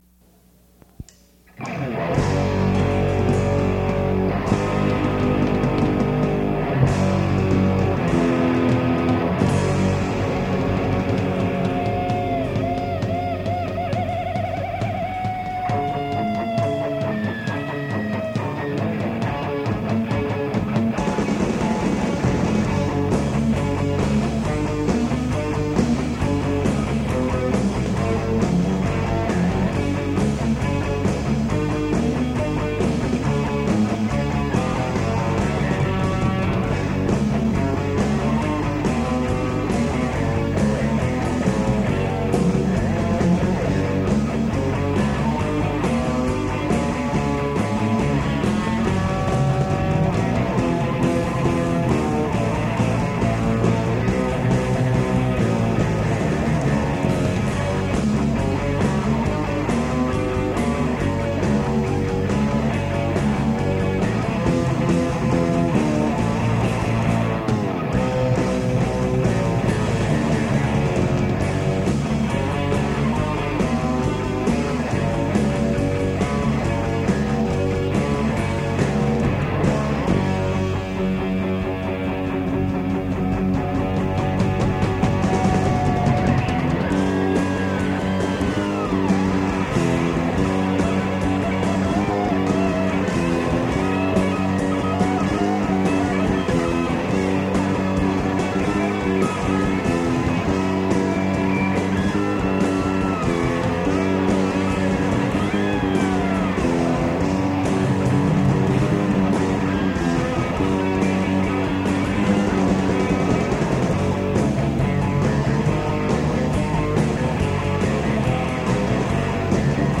The songs were originally recorded using an 8 track.
That's right folks, two, count 'em, two drummers!